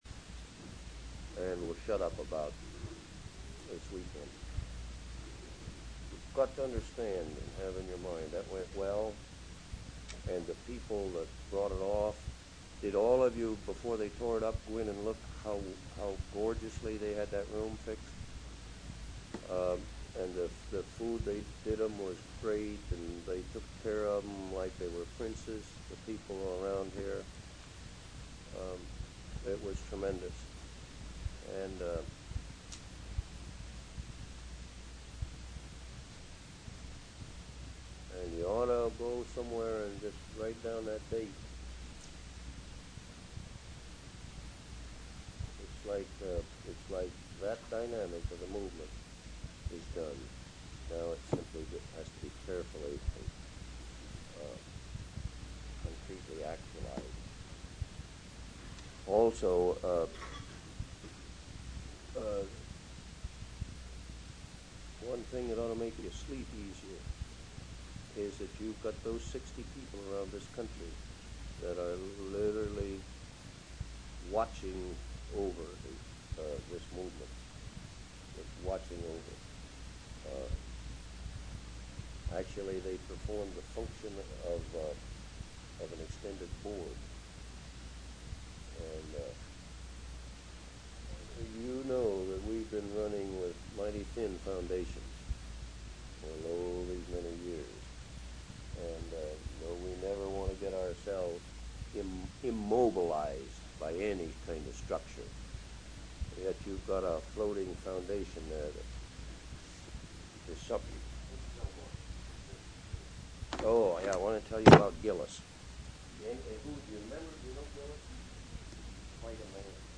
It appears to have been made shortly after an important gathering of colleagues.
I'm sorry the sound is as low as it is on much of this recording.